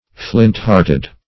Search Result for " flint-hearted" : The Collaborative International Dictionary of English v.0.48: Flint-hearted \Flint"-heart`ed\, a. Hard-hearted.